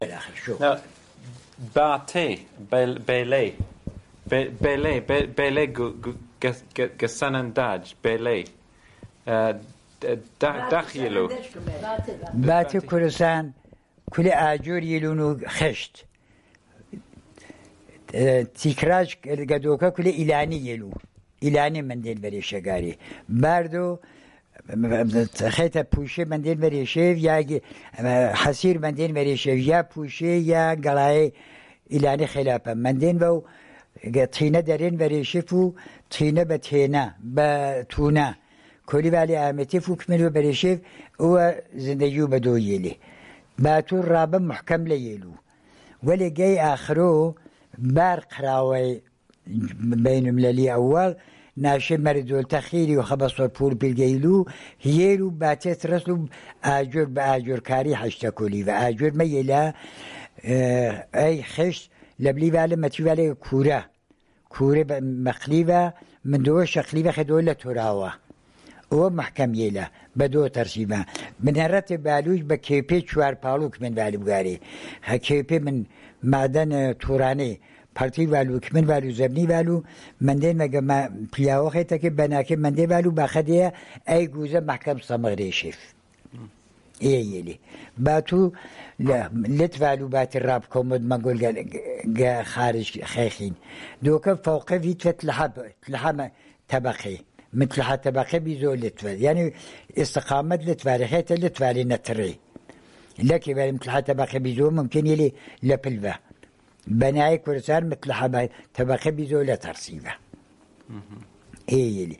Sanandaj, Jewish: Houses